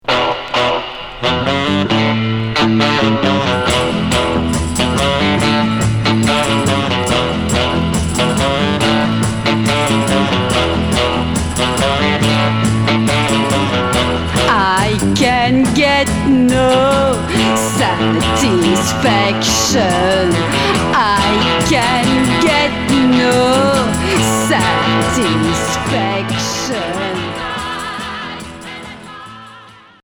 Pop beat Premier EP retour à l'accueil